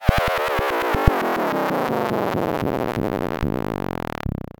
ギャグ・アニメ調（変な音）